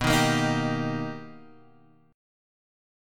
B+M7 chord